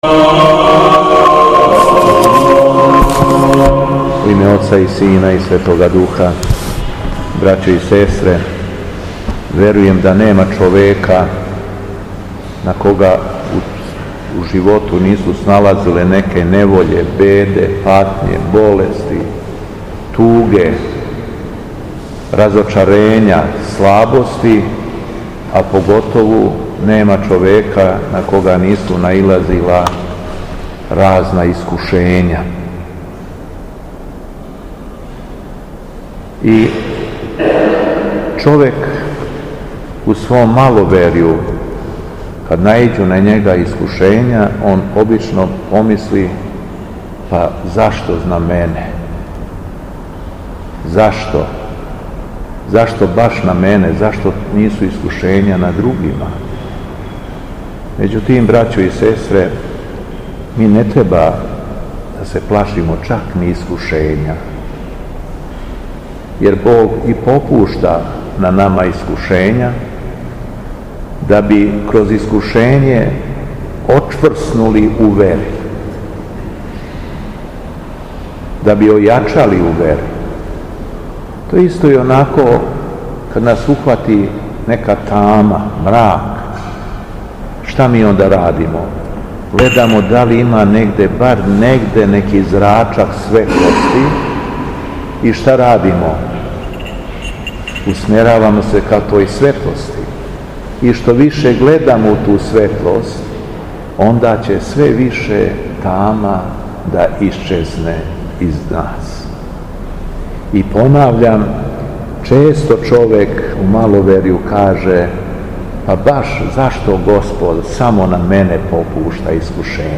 Беседа Његовог Високопреосвештенства Митрополита шумадијског г. Јована
У наставку свете литургије, а после прочитаног јеванђелског зачала митрополит Јован се обратио верном народу речима: